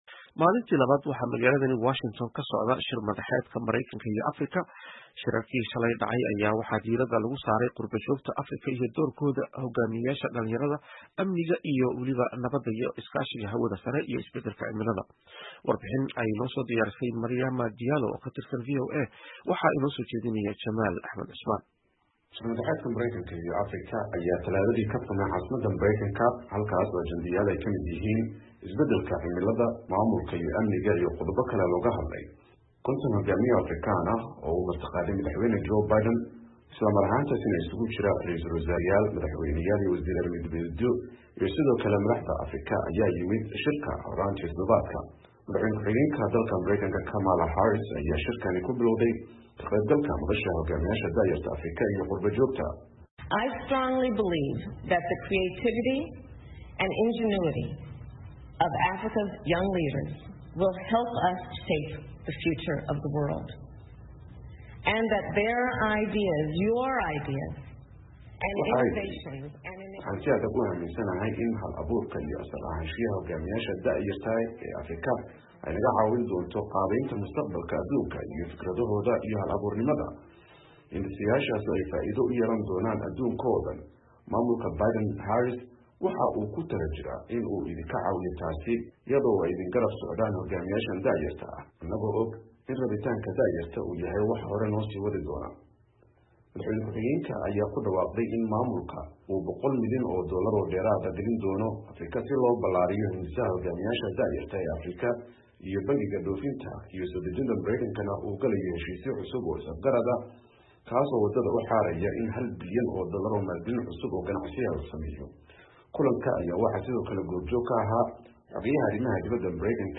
Warbixin ku saabsan maalintii labaad ee shir madaxeedka Mareykanka iyo Afrika